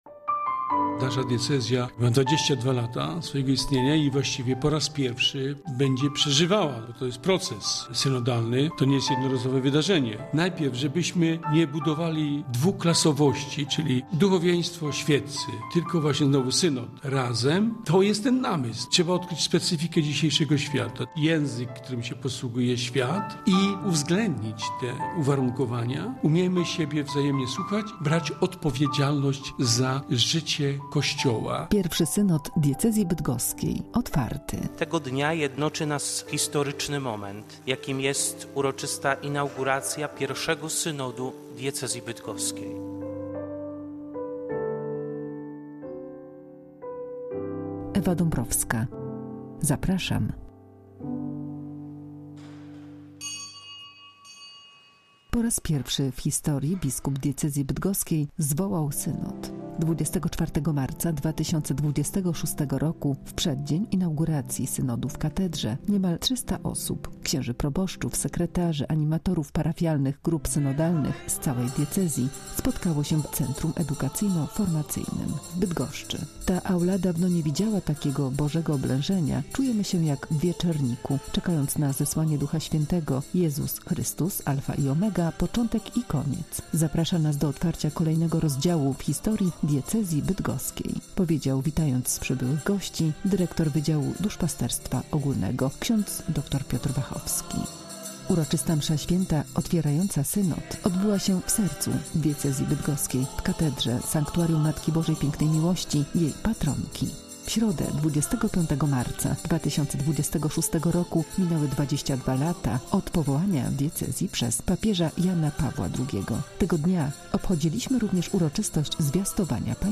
Medialny zapis historycznego momentu Relacja radiowa pozwala na ponowne prześledzenie przebiegu sesji inauguracyjnej oraz zapoznanie się z najważniejszymi przesłaniami, jakie padły w murach katedry pw. św. Marcina i Mikołaja.